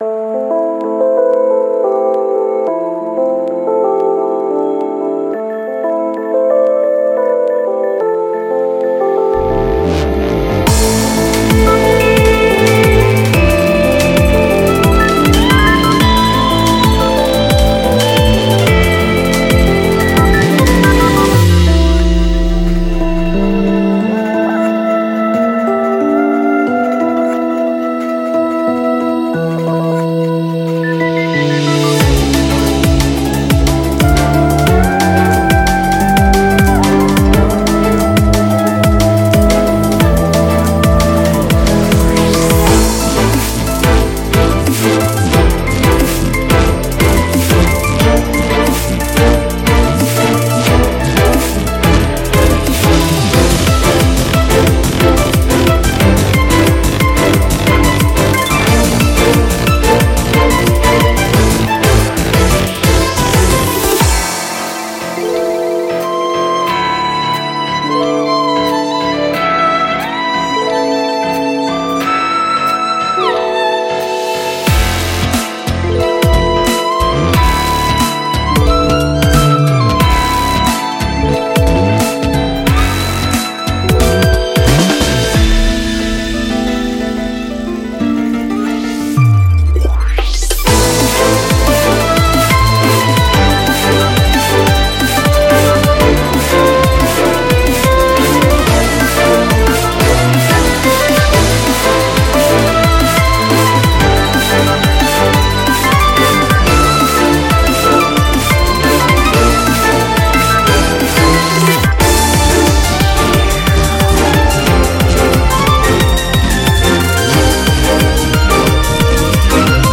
BPM90-180
Audio QualityMusic Cut